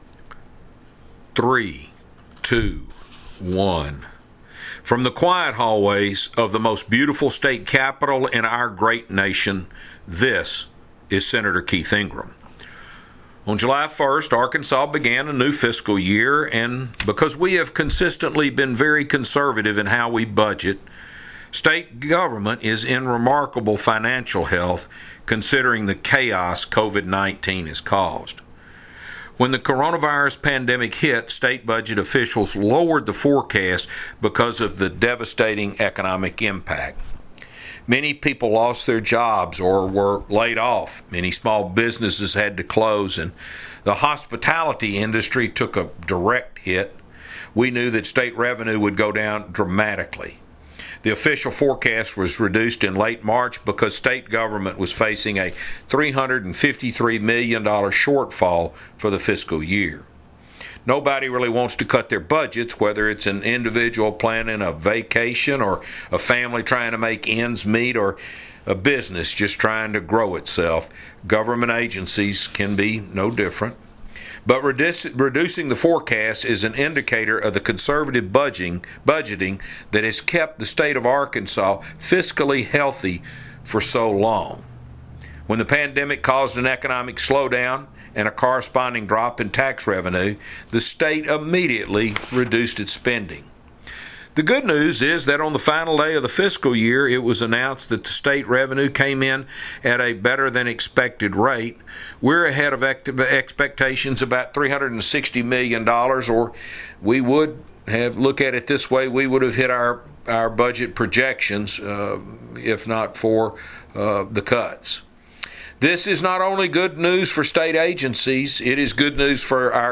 Weekly Address – July 10, 2020 | 2020-07-10T13:30:06.118Z | Sen.